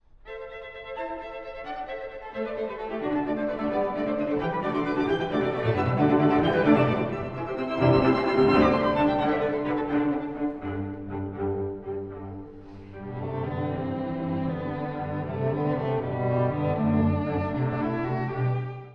(第一主題) 古い音源なので聴きづらいかもしれません！
舞曲風でありながら、洗練された構成感を持つ楽章。
最後は熱情を持ったまま、晴れやかに締めくくります！